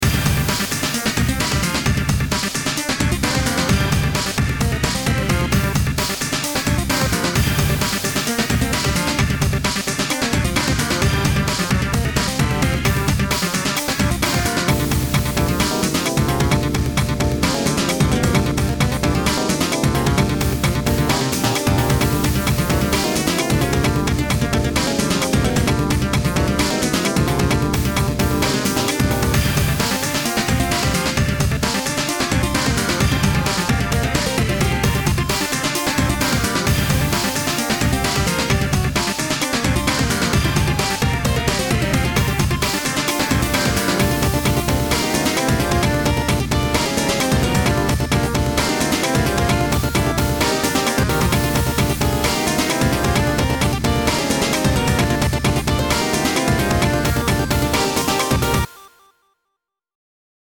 battle theme